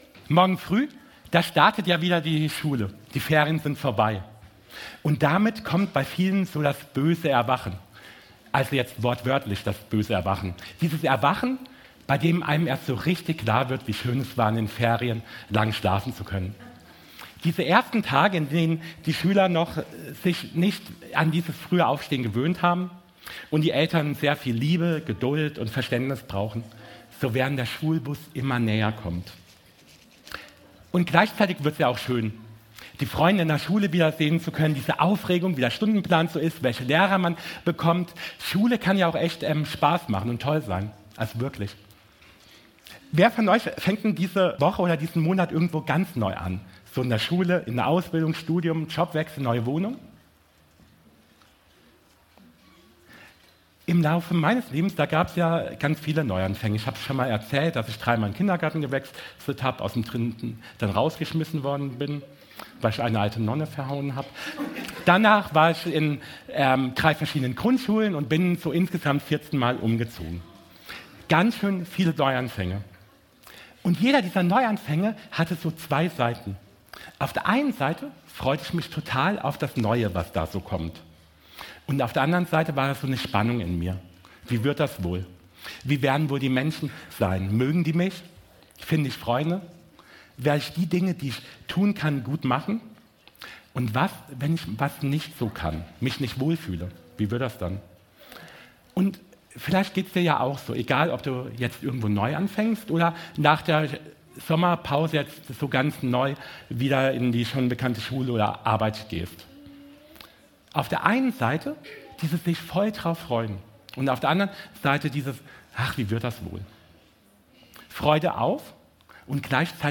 Gottesdienst zum Schuljahresstart
Predigt